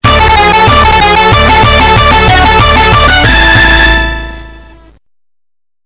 クラシックの名曲をアレンジしたボーナスサウンド！